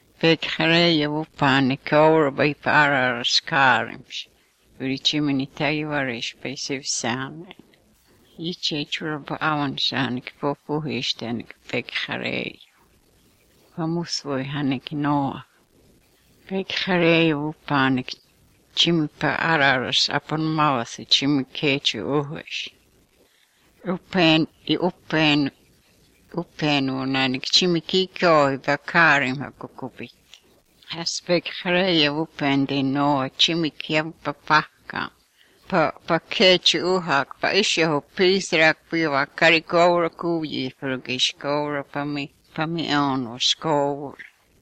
6 April 2014 at 5:49 pm It’s more the voice of the speaker than what she says that reminds me of a clip about the Haida language I saw on YouTube the other day.